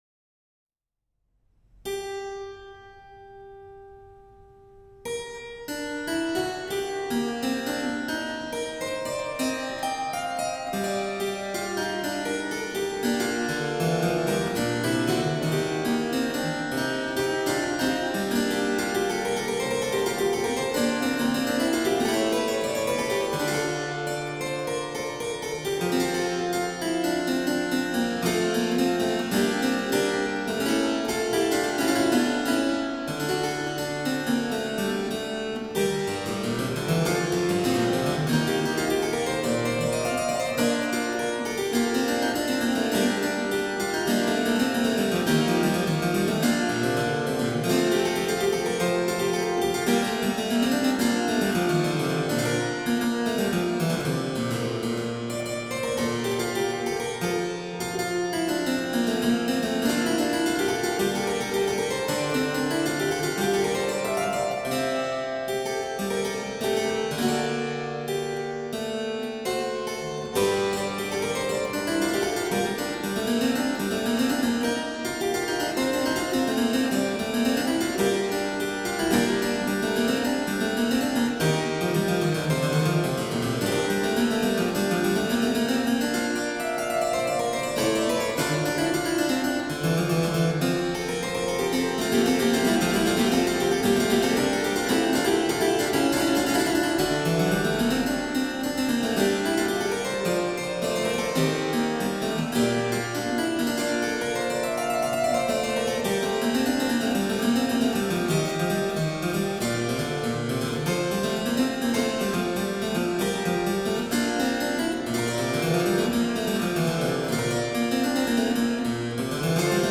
harpsichord